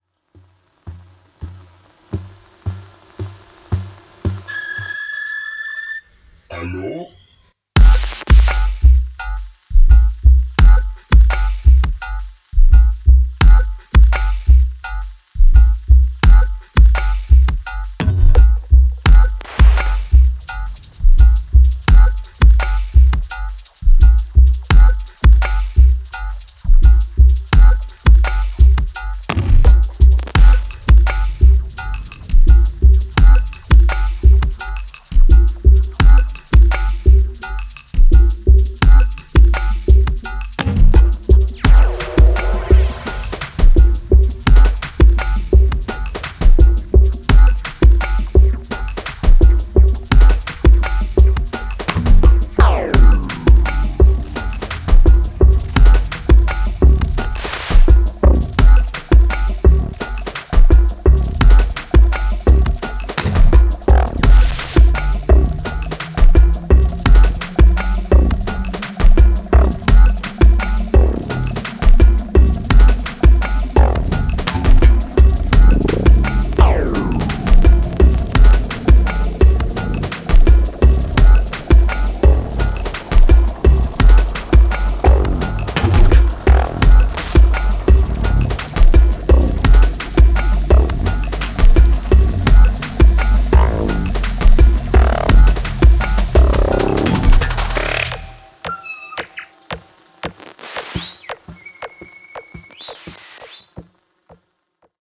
こちらも廃墟感覚のドラムがフロアを震わすインダストリアル・ダンスホール/デンボウ